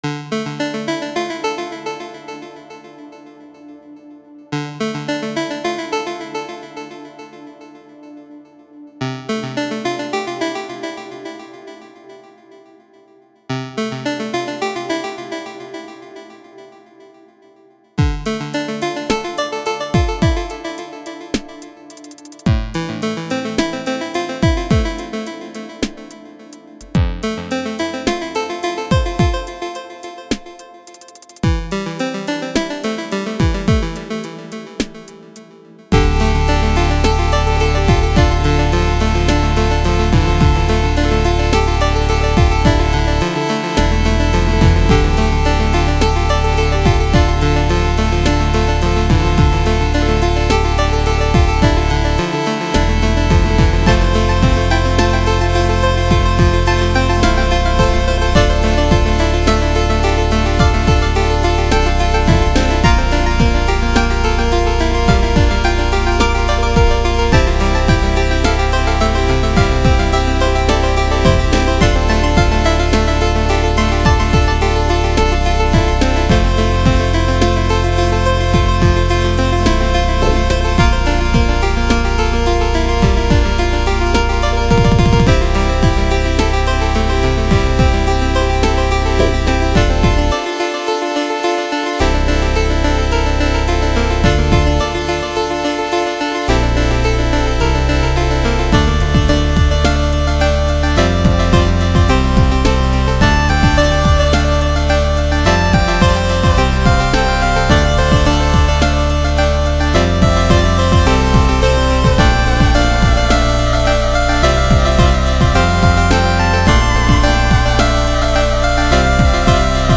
An electronic and chiptune mixed track.